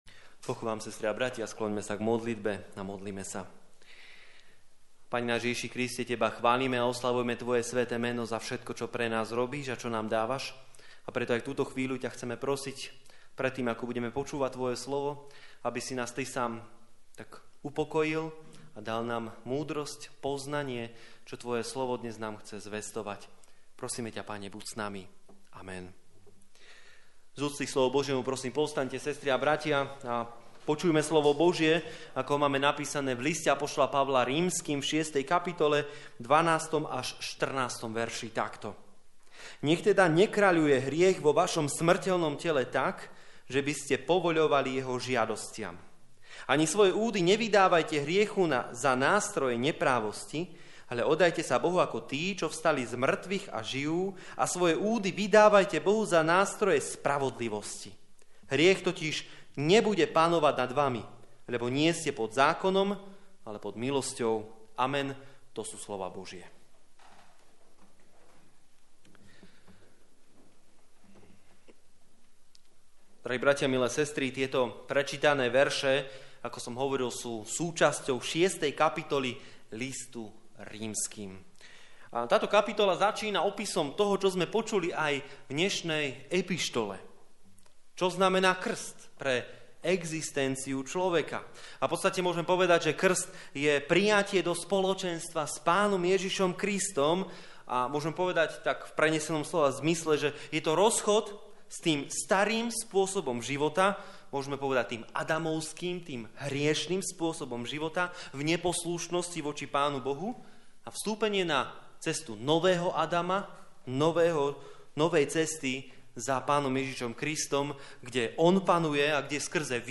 Service Type: Služby Božie po Svätej Trojici